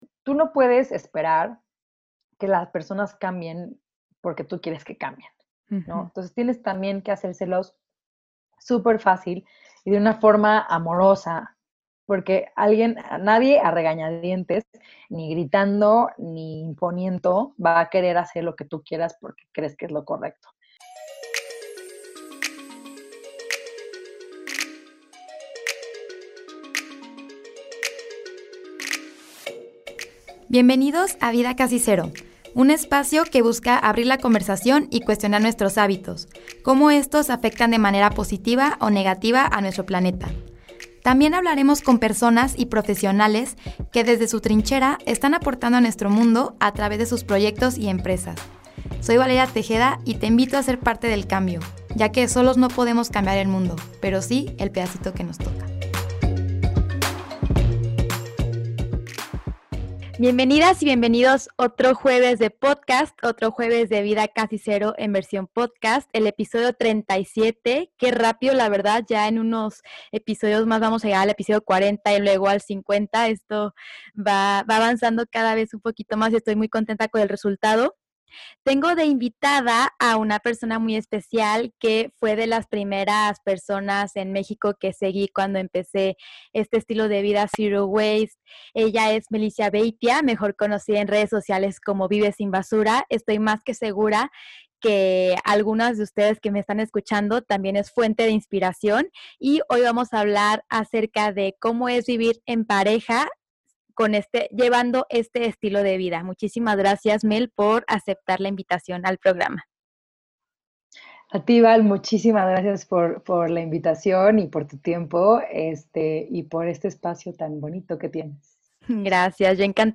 Fue una plática más informal que lo de costumbre